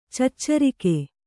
♪ caccarike